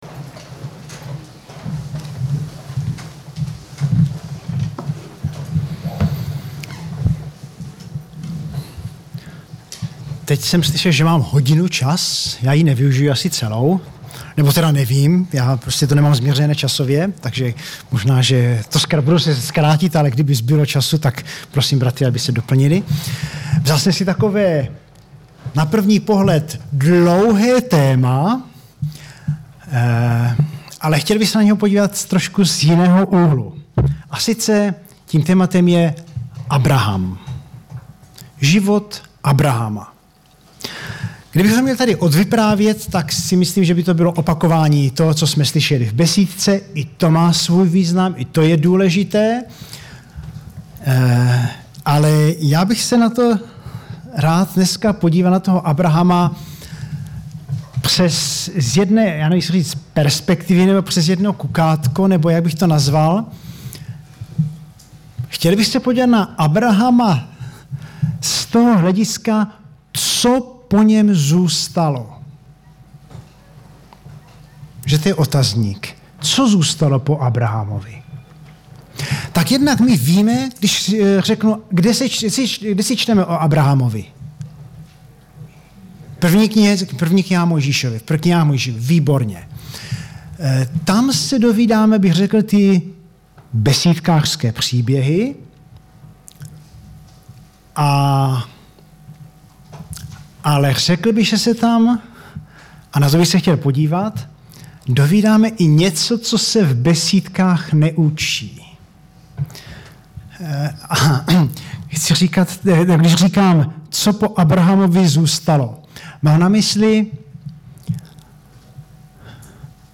Kázání